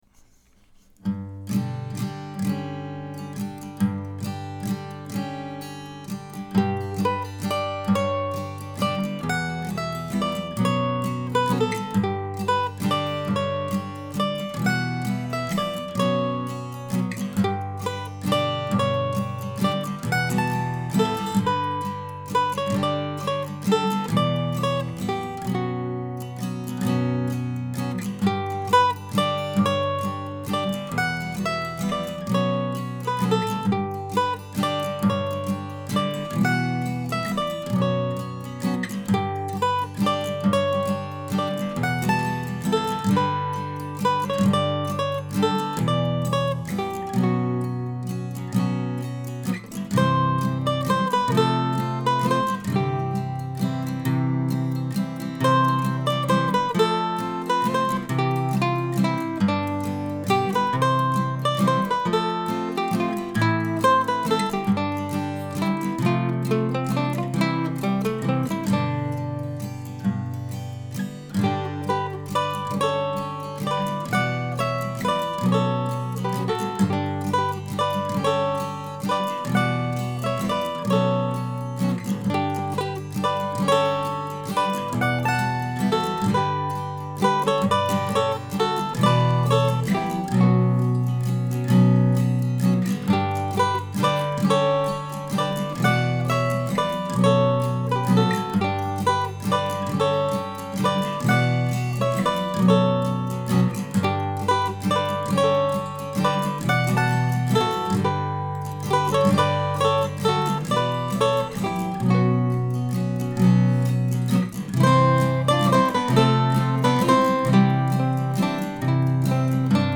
This week's new tune is a waltz that takes its title from a lovely area of Piscataquis County, Maine known as Dover South Mills.